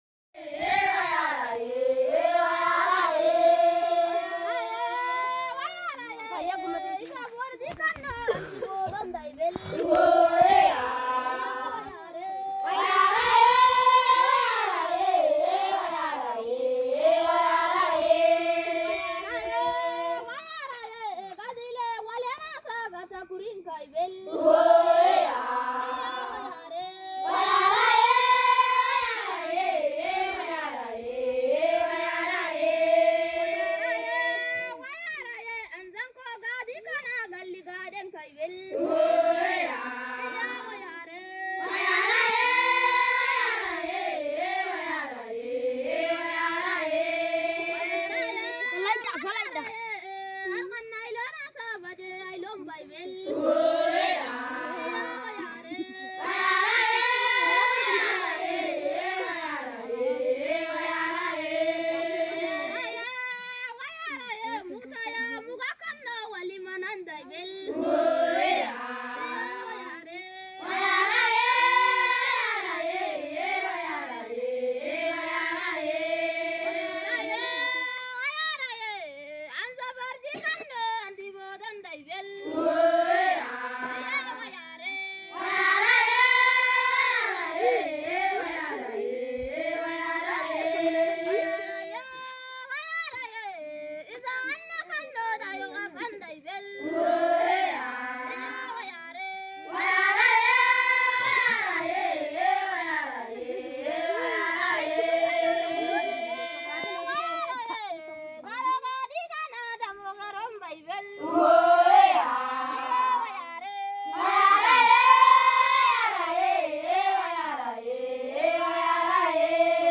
Canto hamer 2
Canto-hamer-2.m4a